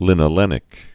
(lĭnə-lĕnĭk)